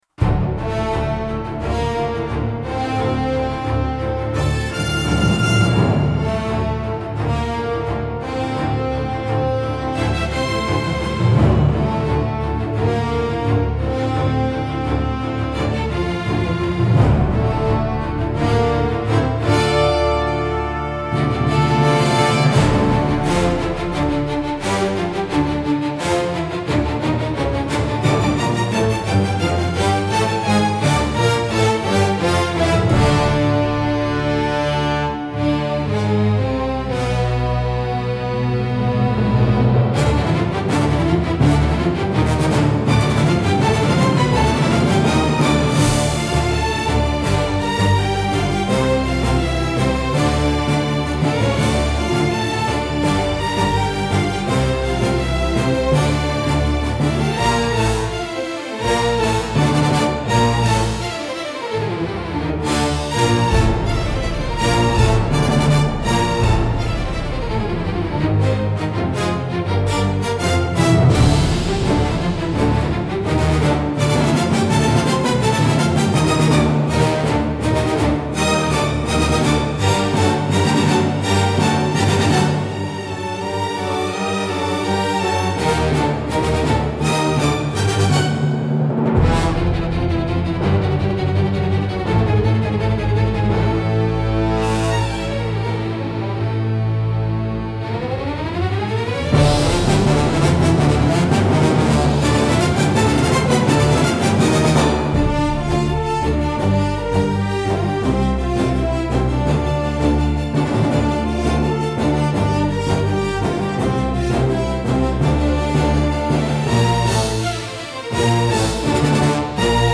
Оркестровые версии(1987,2024
• Инструментал